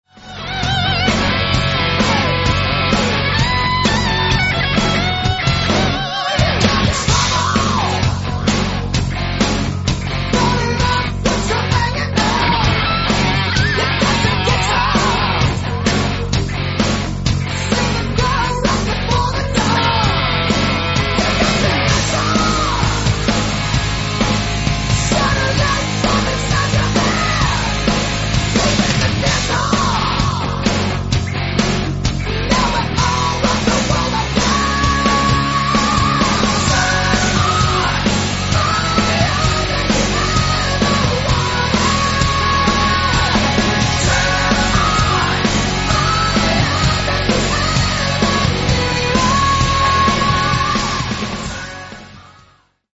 Sounds like: Cinderella II: Dreams Come True